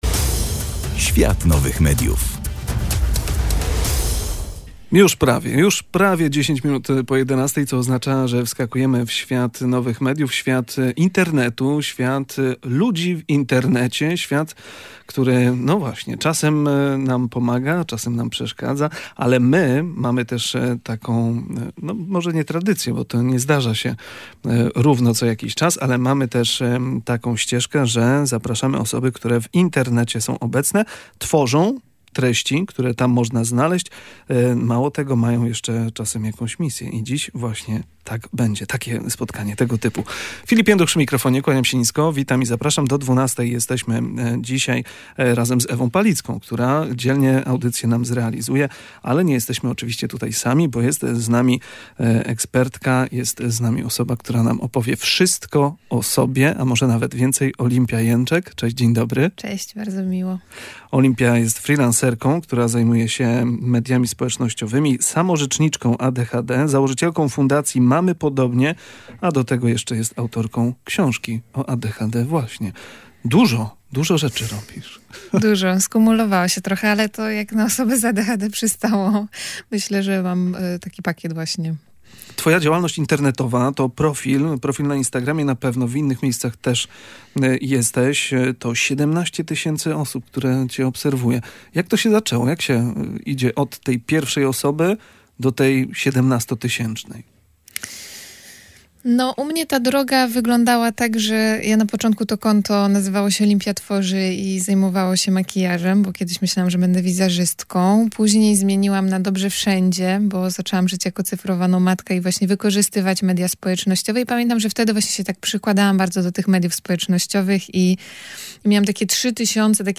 Kolejne wydanie audycji „Świat Nowych Mediów” było okazją do spotkania z twórczynią treści internetowych.